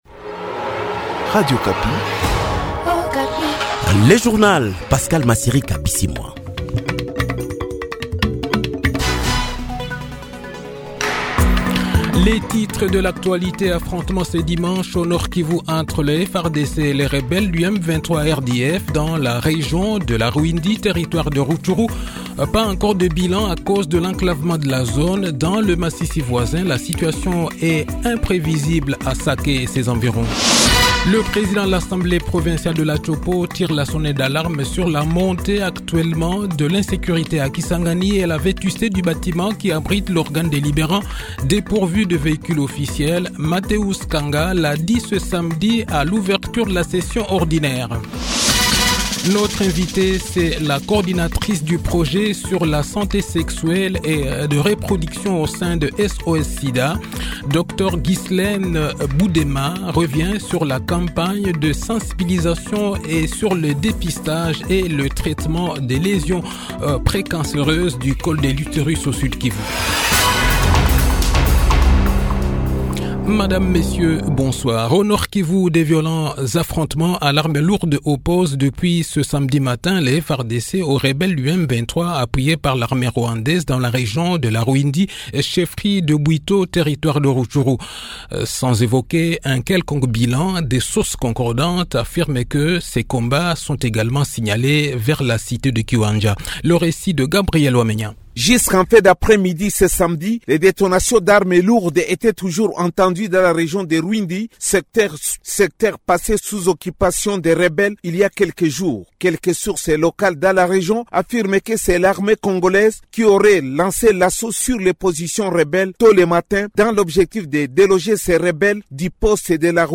Journal Soir 18H